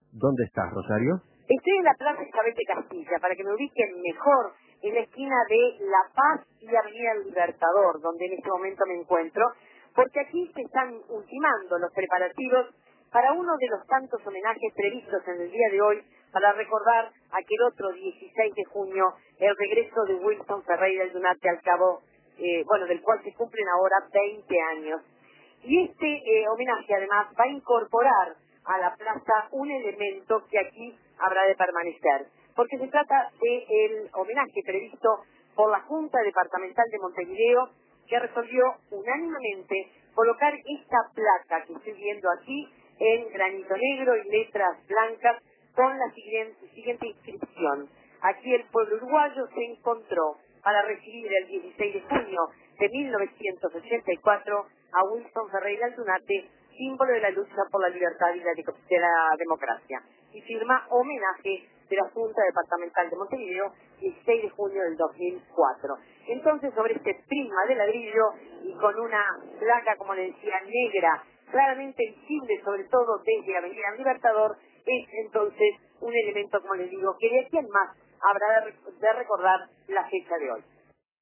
Preparativos para el homenaje a Wilson a 20 años de su regreso. Móvil